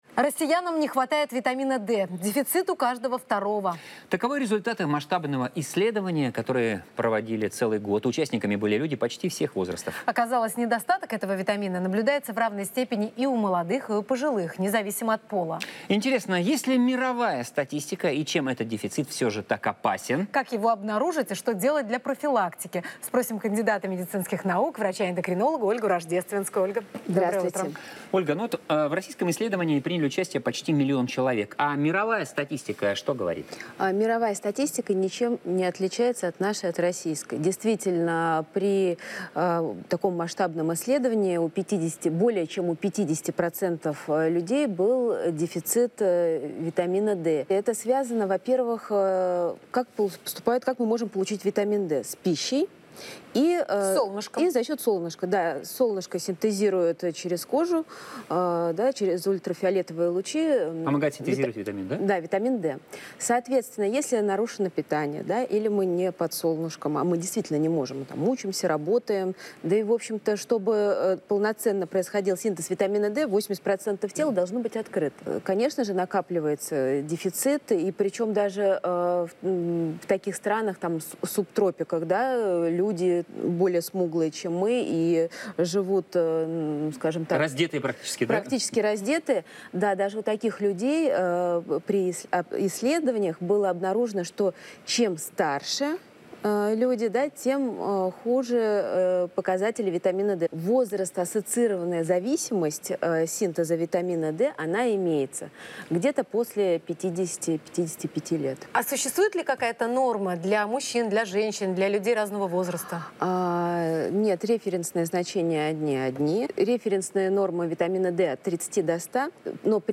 скачать интервью в txt формате